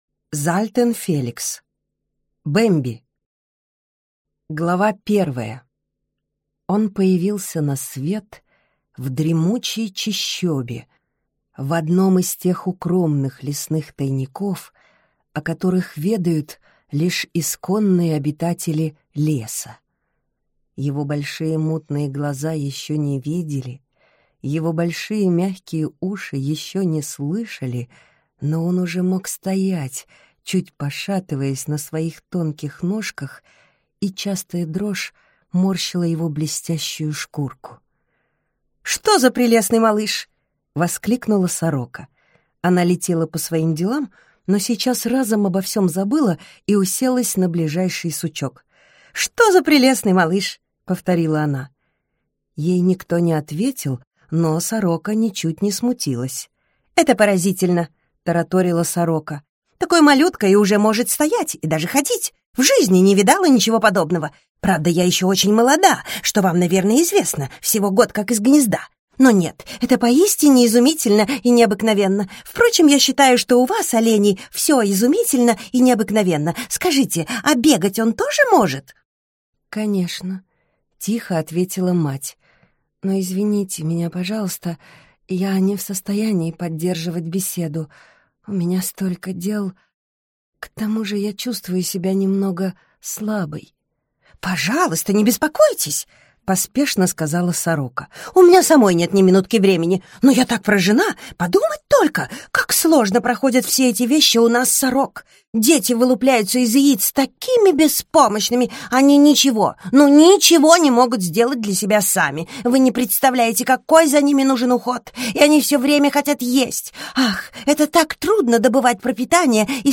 Аудиокнига Бемби | Библиотека аудиокниг
Прослушать и бесплатно скачать фрагмент аудиокниги